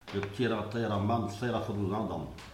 Localisation Saint-Jean-de-Monts
Langue Maraîchin
Catégorie Locution